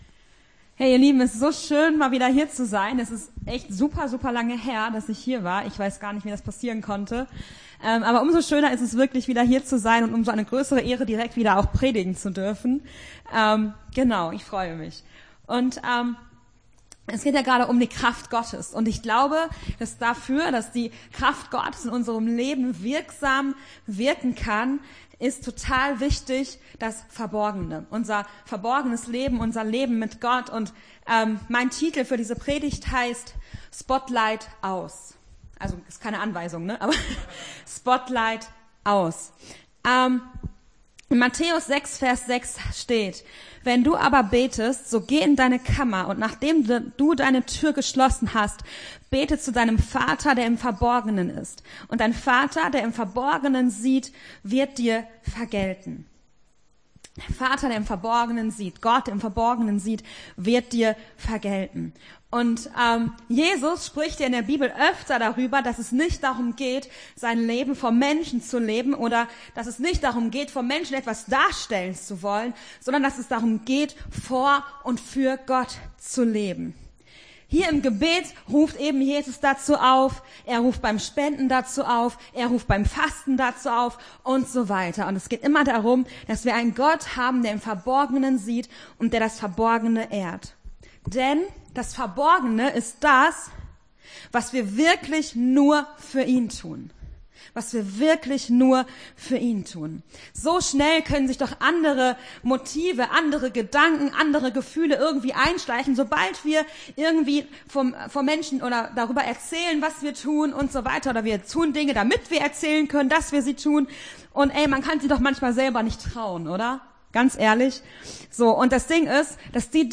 Gottesdienst 13.03.22 - FCG Hagen